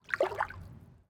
sounds / mob / dolphin / swim4.ogg